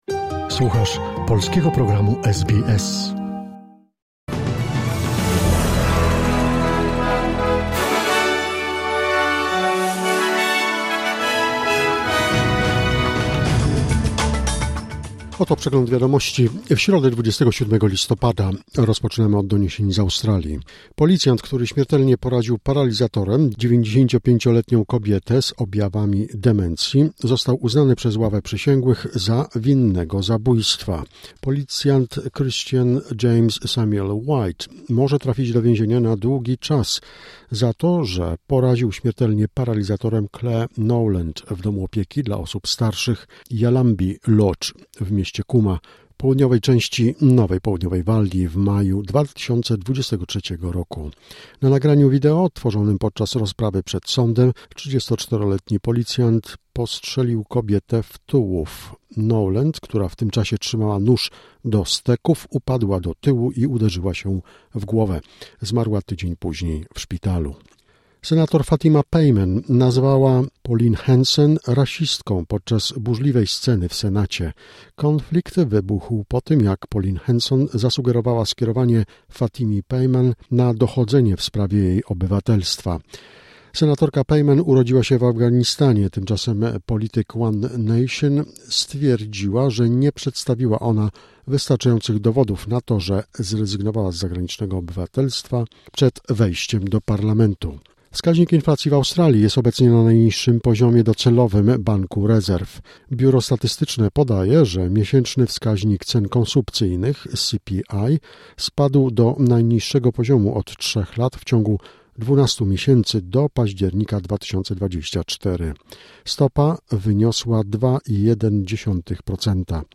Wiadomości 27 listopada SBS News Flash